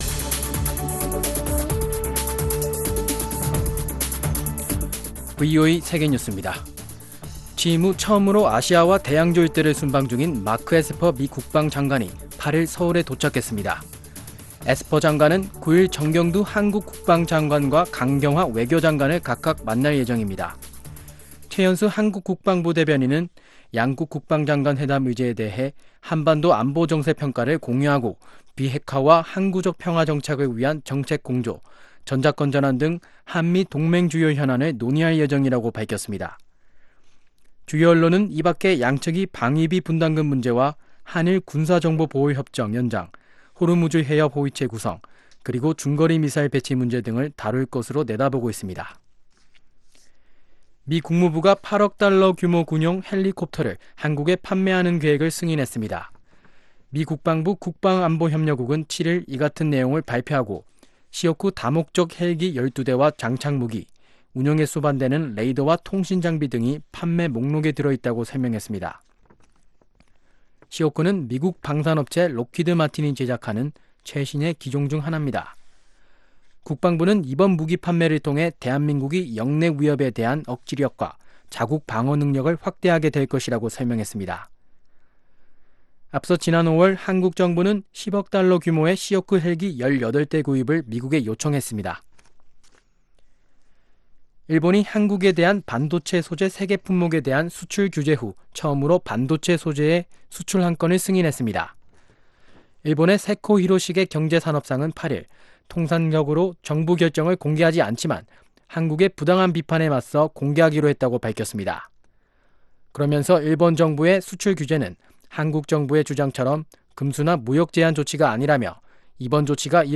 VOA 한국어 아침 뉴스 프로그램 '워싱턴 뉴스 광장' 2019년 8월 9일 방송입니다. 마이크 폼페오 미국 국무장관이 북한과의 실무협상이 수주 안에 재개 되기를 기대한다고 말했습니다. 미 국무부는 한-일 군사정보보호협정에 관한 한국 청와대 정의용 국가안보실장의 발언에 대해 미-한-일 3국 관계의 중요성을 거듭 강조했습니다.